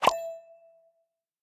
achievementUnlocked.ogg